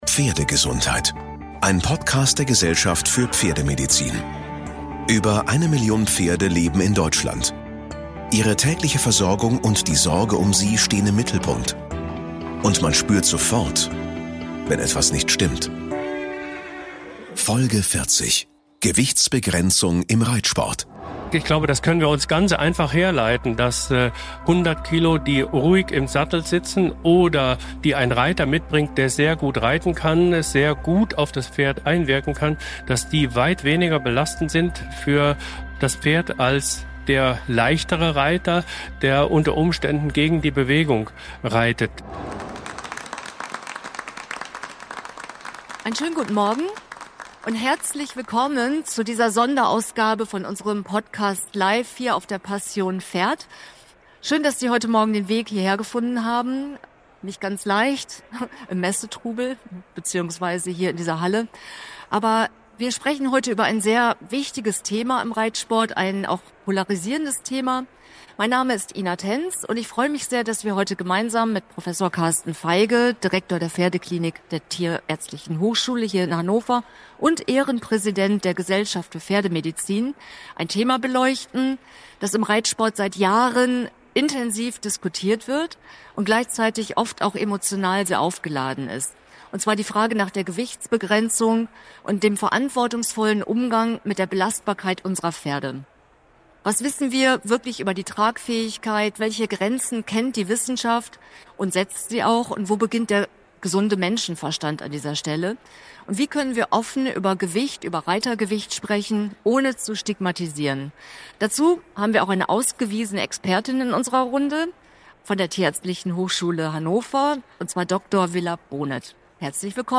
Diese Folge ist eine Live-Produktion von der Messe "Passion Pferd" im Dezember 2025.